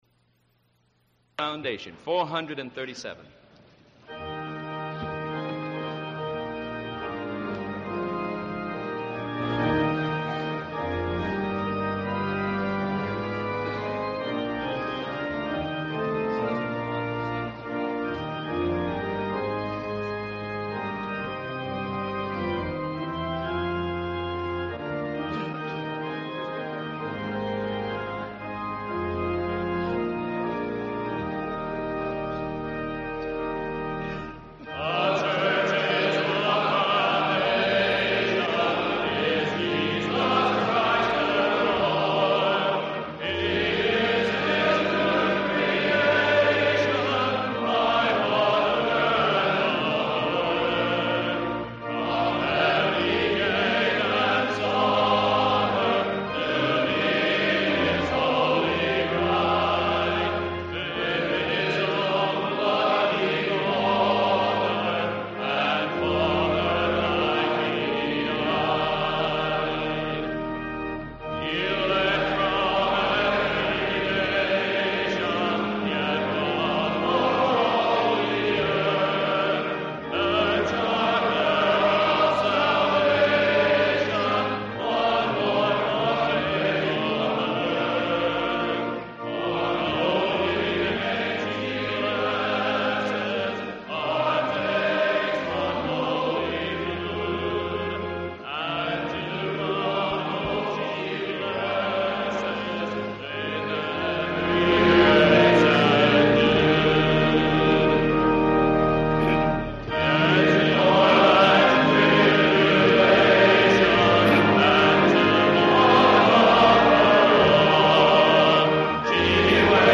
Audio Files, Second PCA General Assembly (1974)
Call to Order & Opening Worship